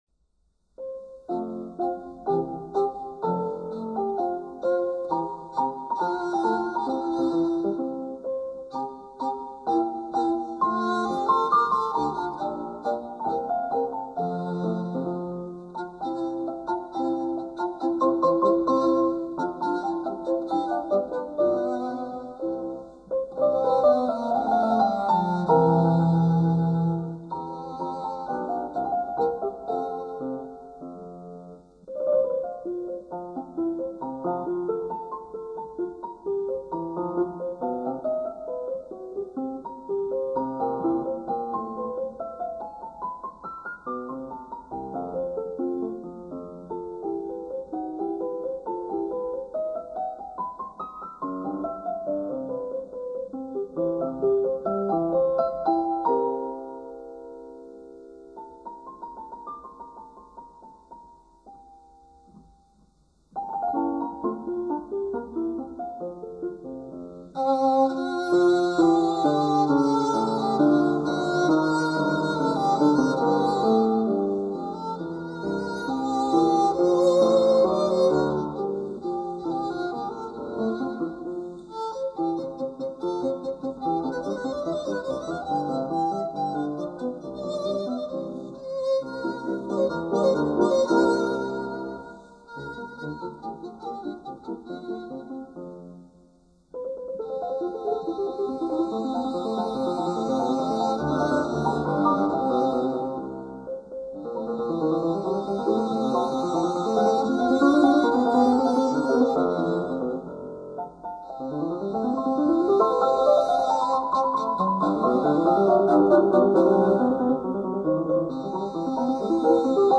Violoncello
Pianoforte